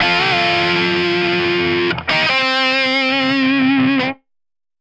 Сэмпл соло гитары (Метал): Guitar Solo E
Тут вы можете прослушать онлайн и скачать бесплатно аудио запись из категории «Metal (Метал)».
Sound_11951_GuitarSoloE.ogg